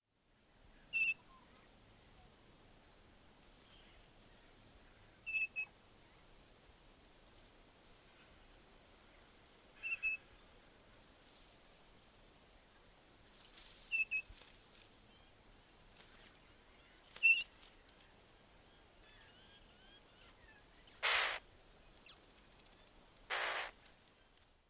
Click on the audio below to hear the call, which is interrupted by the harsh warning call of an adult when it became aware of our presence.
shrike-thrush-chick.mp3